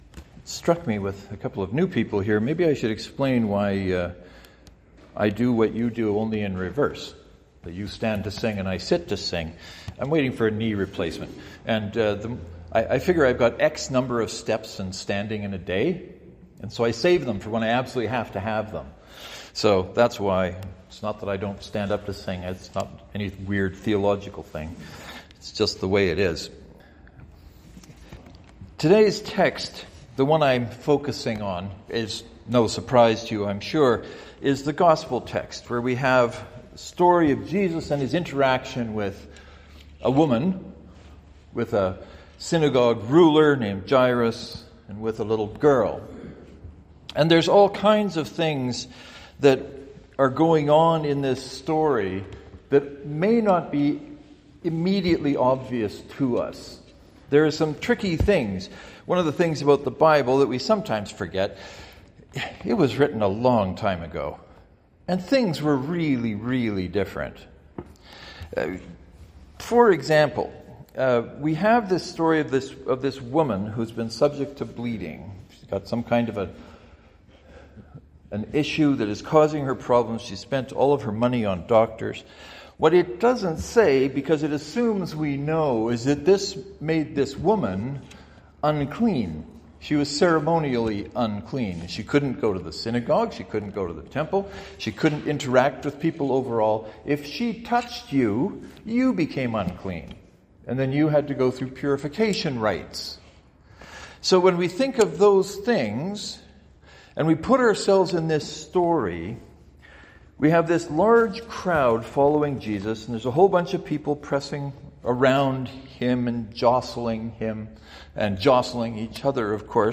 There is a bit of introduction about why I sit to sing. The sermon proper begins at 28 seconds.